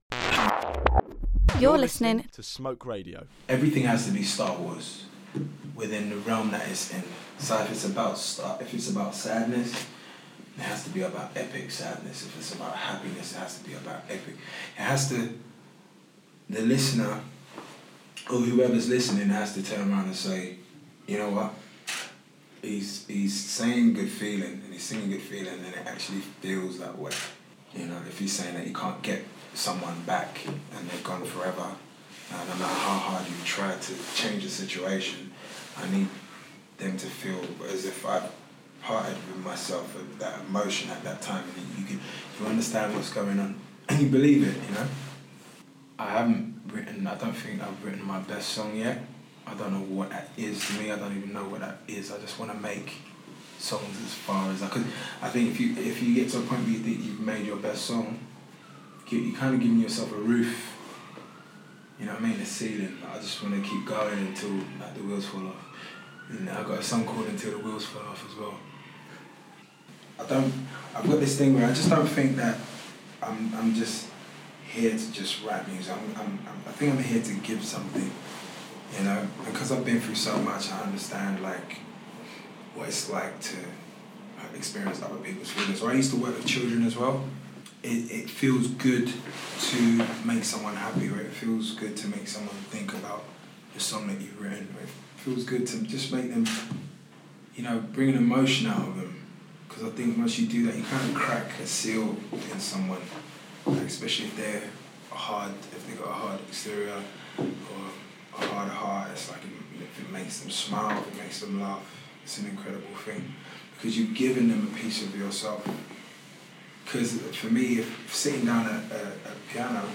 This interview contains mature content.